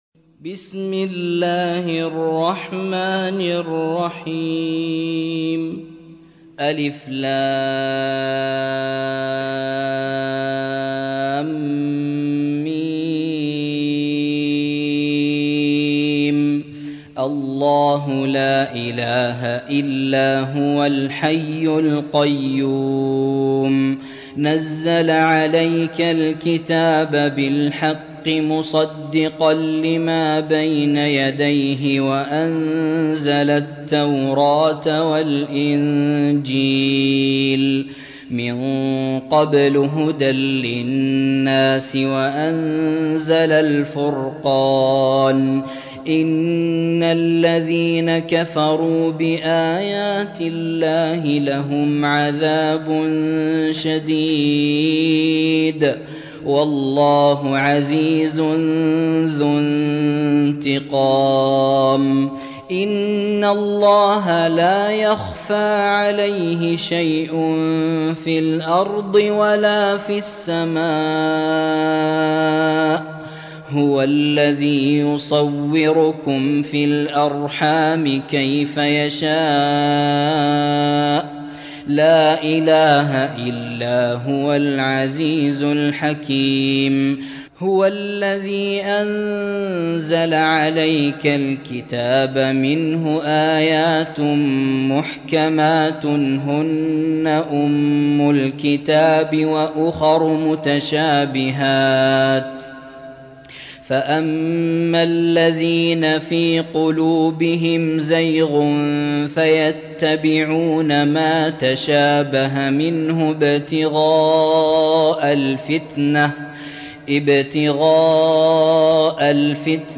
3. سورة آل عمران / القارئ